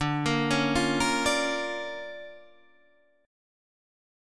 DmM7#5 chord